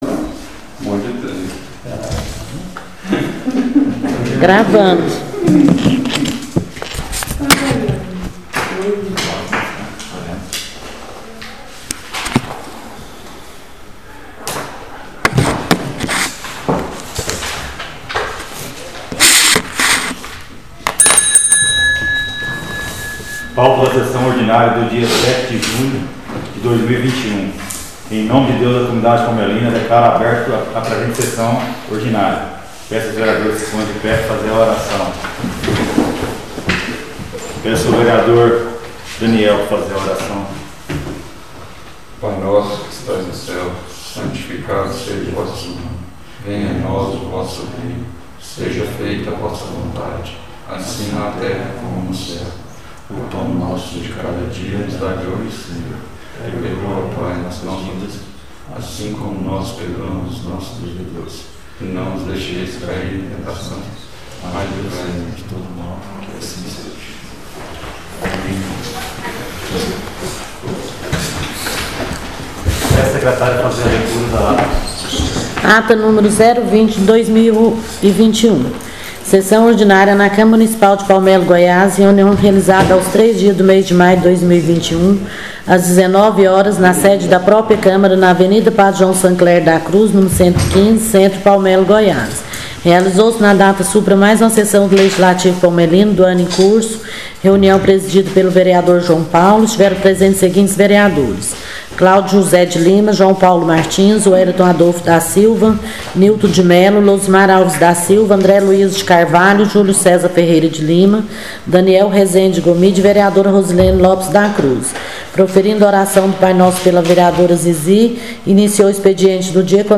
SESSÃO ORDINÁRIA DIA 07/06/2021